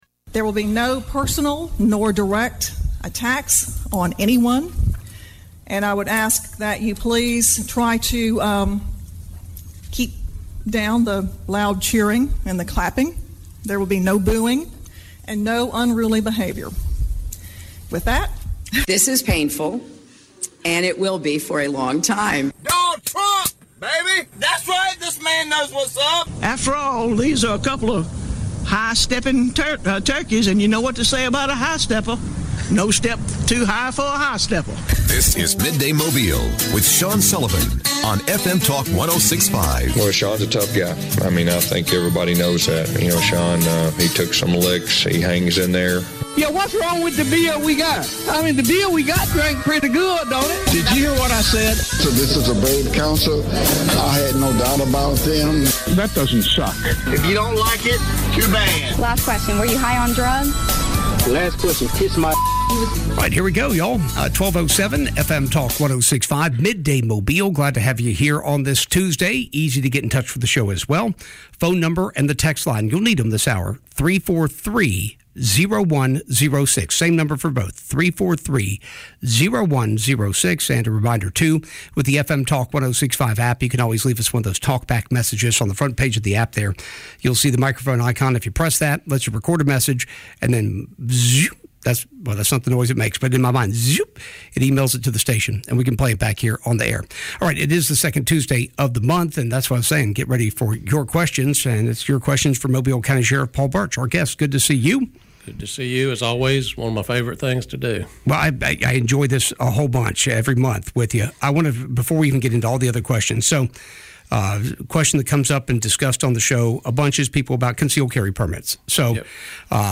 ICYMI On Midday Mobile this afternoon it was time for our monthly segment of "🚨Ask the Sheriff🚨" TEAM SHERIFF Paul Burch stopped by to answer questions about guns, permits, and traffic ticket. There was also talk about a certain Halloween Display...